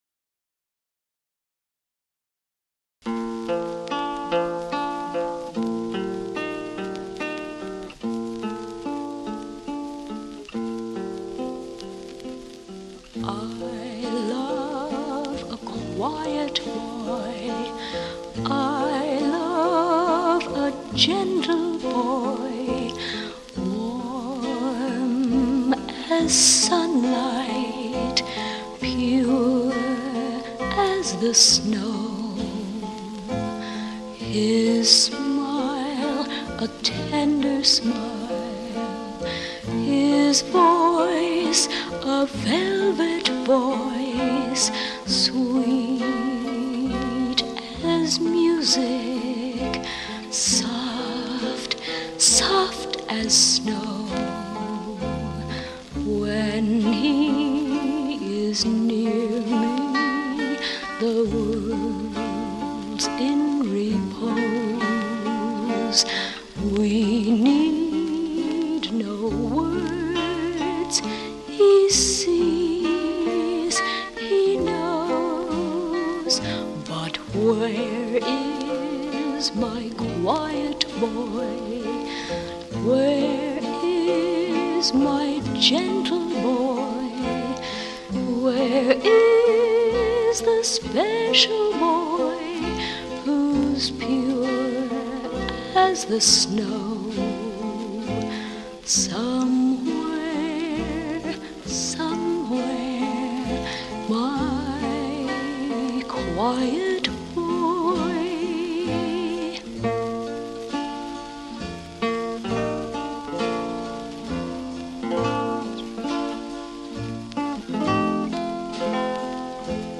as well as her naturally fresh vocal quality.